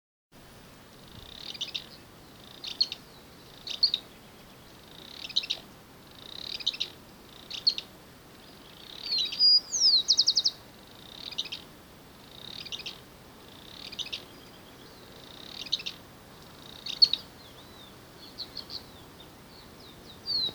Barullero (Euscarthmus meloryphus)
Fase de la vida: Adulto
Localización detallada: Área Natural Yrigoyen (Yrigoyen y el Río)
Condición: Silvestre
Certeza: Observada, Vocalización Grabada
barullero.mp3